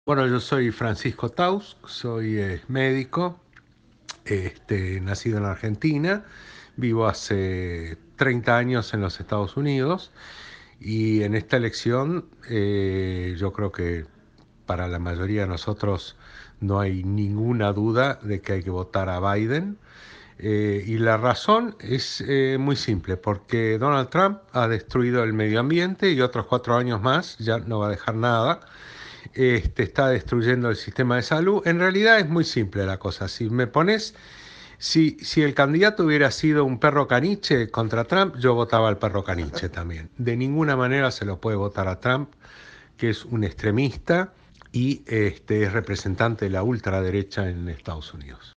Audio. Médico argentino radicado en EE.UU.: "No ninguna duda de que hay que votar a Biden"